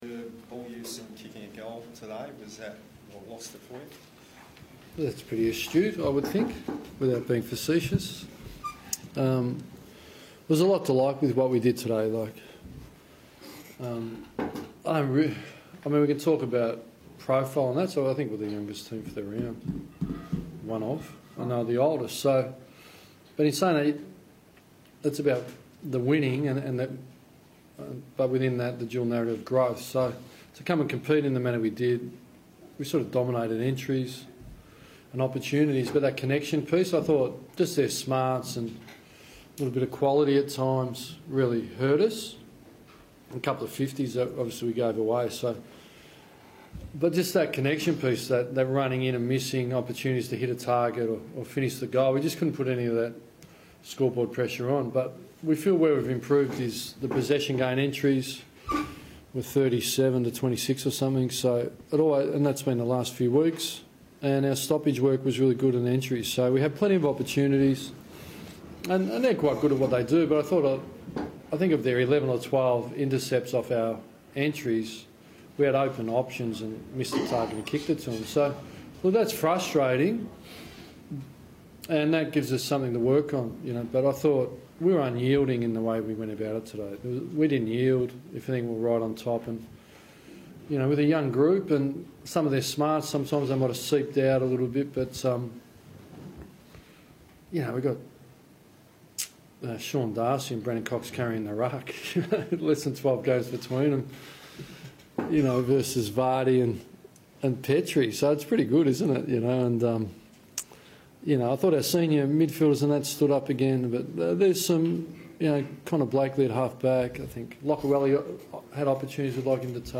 Ross Lyon chats to media after Freo's clash against the Eagles.